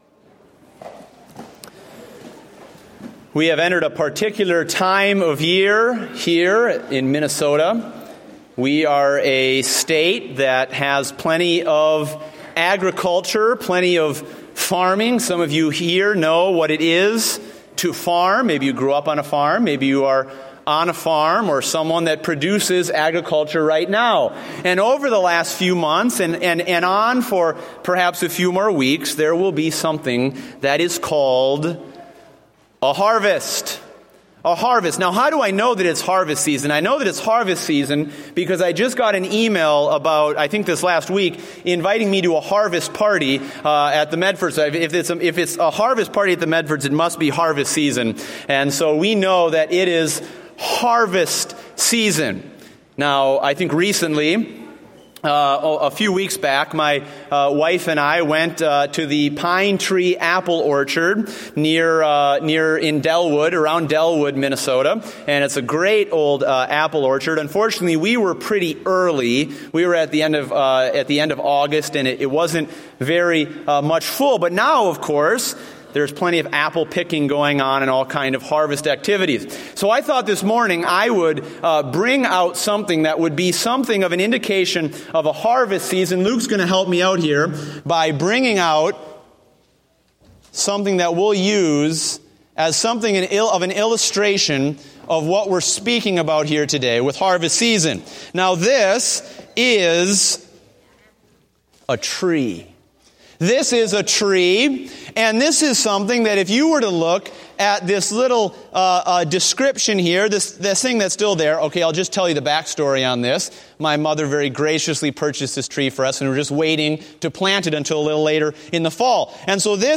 Date: October 4, 2015 (Morning Service)